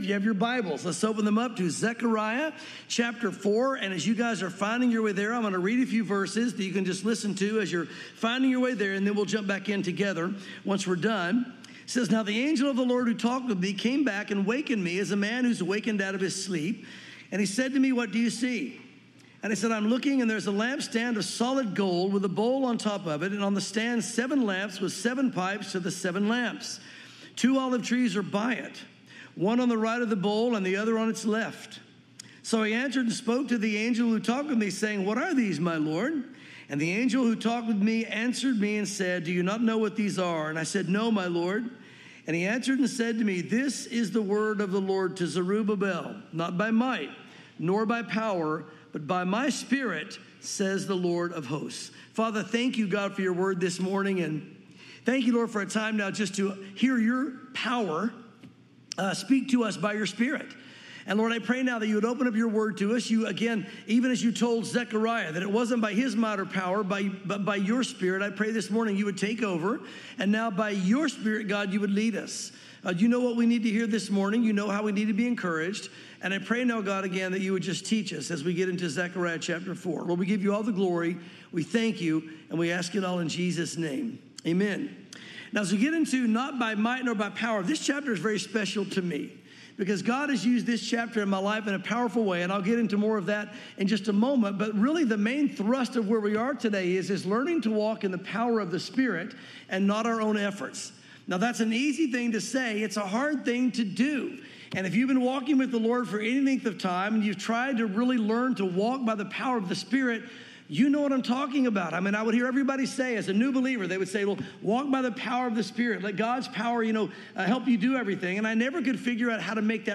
sermons Zechariah 4 | Not by Might Nor by Power